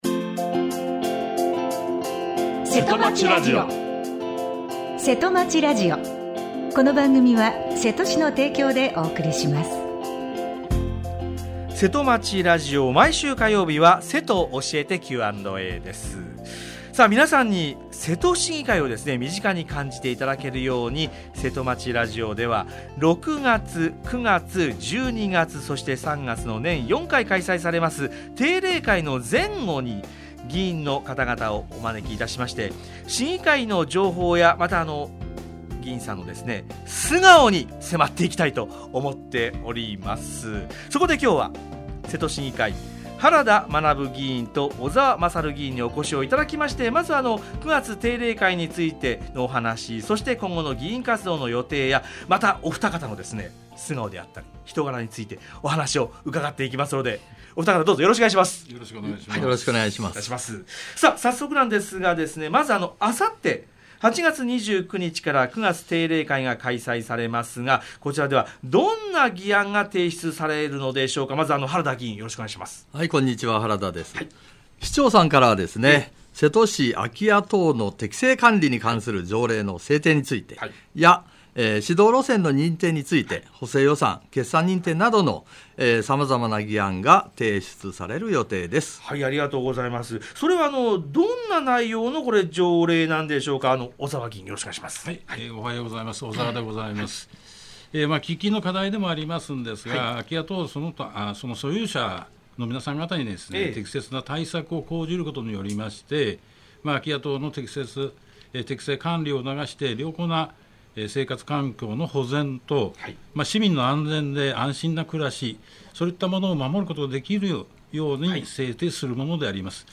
今日は議会からのお知らせです。 瀬戸市議会 原田学議員と小澤勝議員にお越しいただき 「9月の定例会」などについてお話を伺いました。